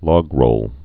(lôgrōl, lŏg-)